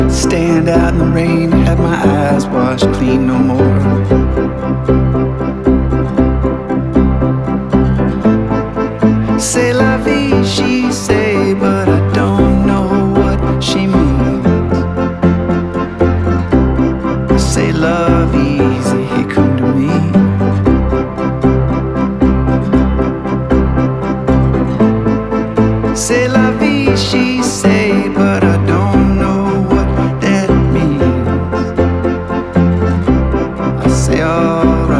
Indie Rock Alternative Rock
Жанр: Рок / Альтернатива